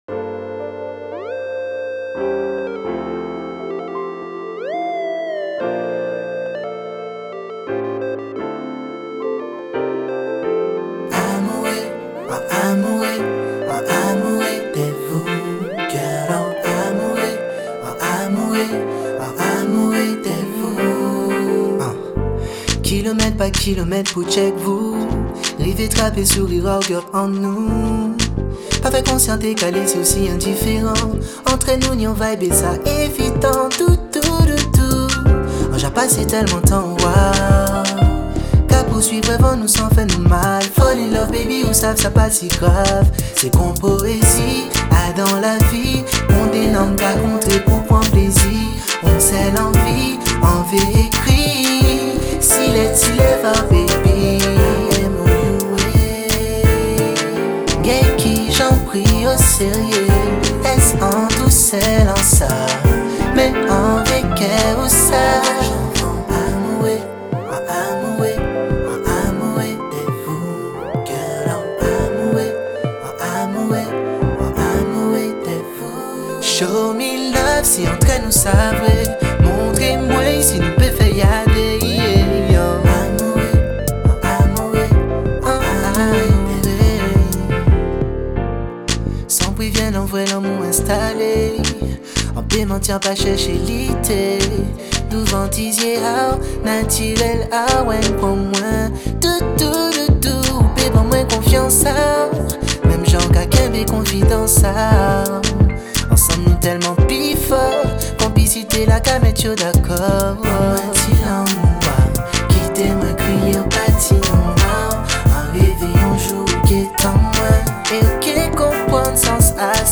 for a solo mixing biguine and jazz.